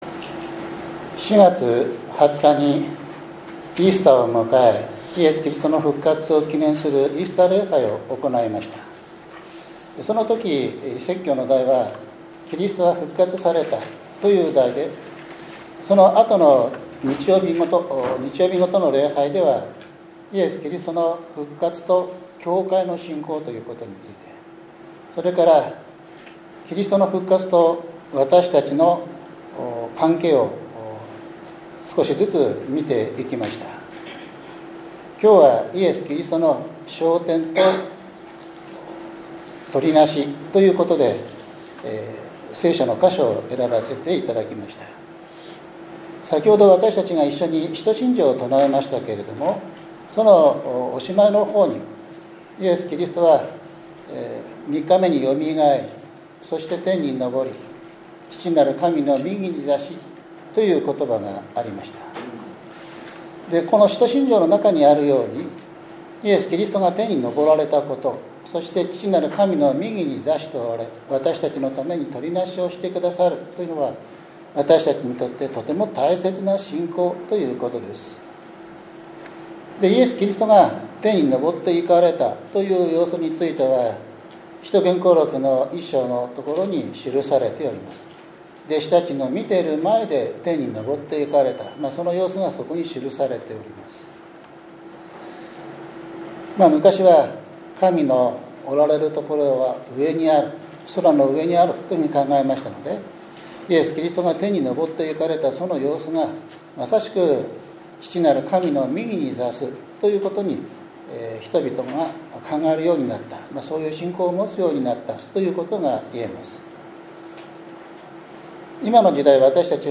５月２５日（日）主日礼拝 詩編１３０編１節～８節 ローマの信徒への手紙８章３４節